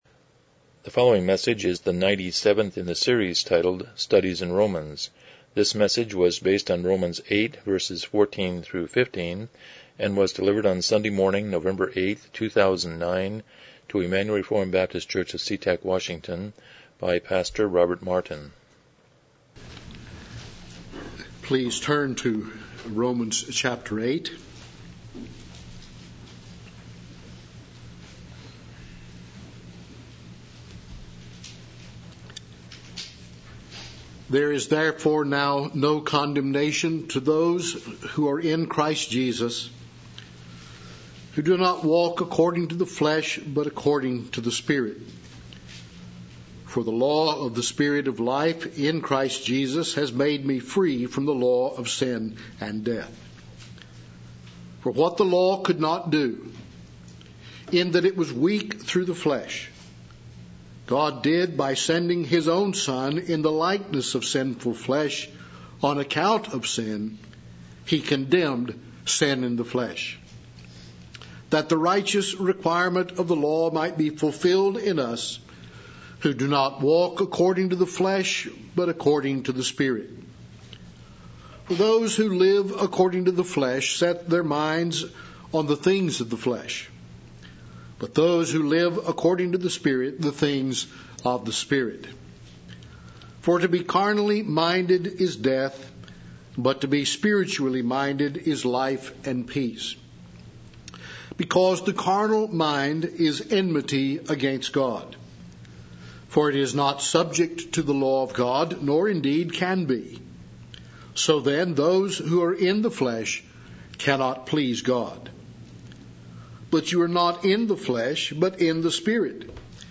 Romans 8:14-15 Service Type: Morning Worship « 55 The First Commandment 80 Chapter 15:3